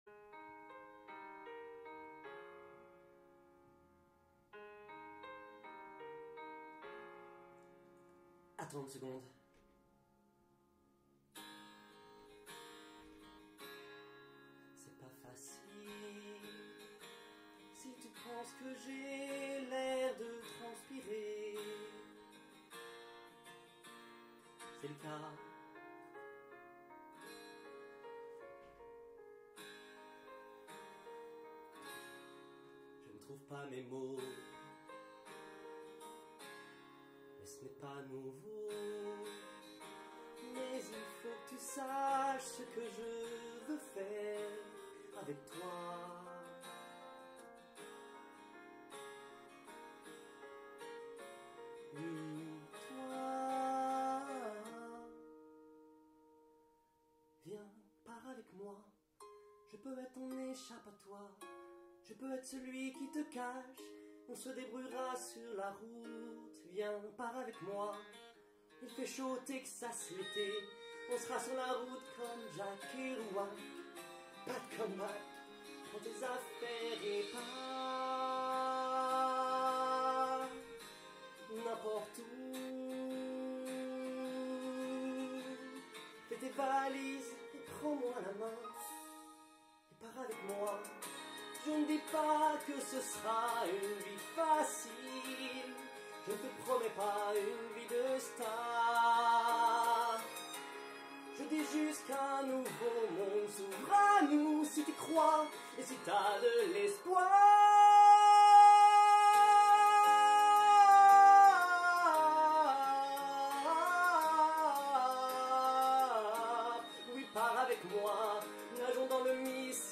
Bande démo chant